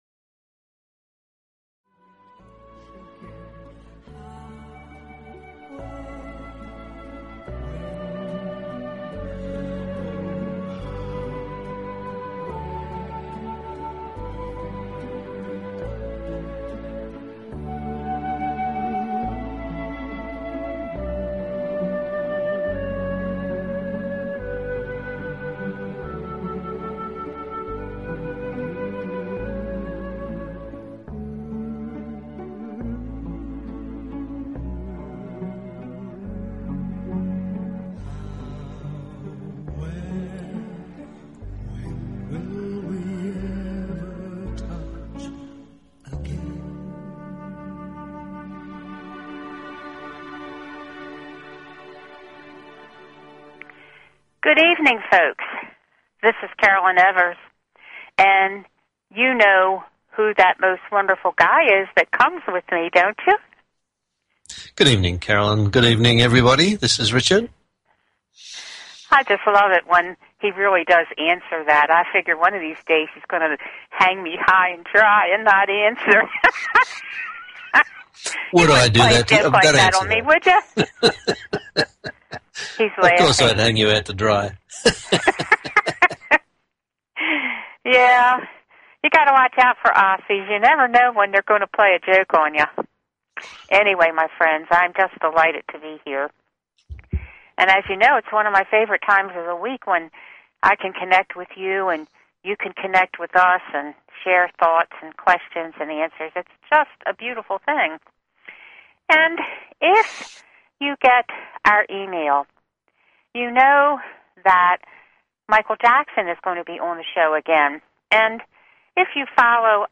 Talk Show Episode, Audio Podcast, The_Messenger and Courtesy of BBS Radio on , show guests , about , categorized as
Repeat of the Michael Jackson Interview from last week